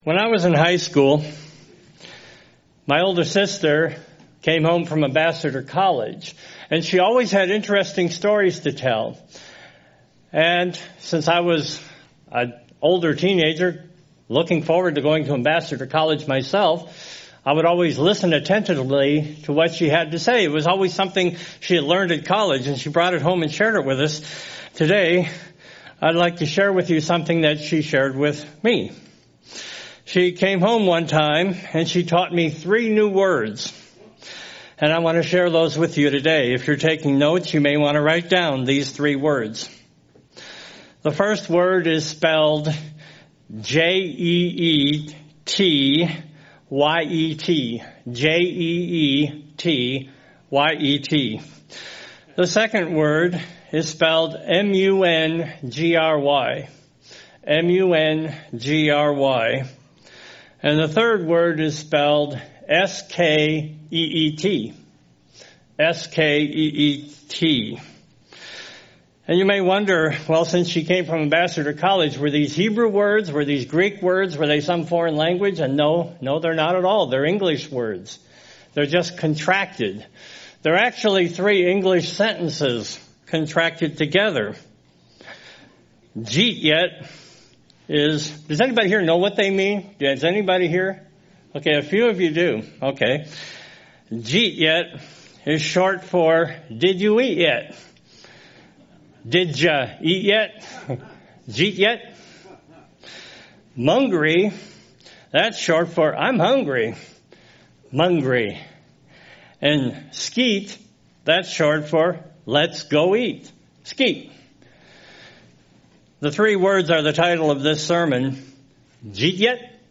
This sermon is on the topic of hunger. First, it will differentiate between the normal hunger we feel between meals and chronic hunger.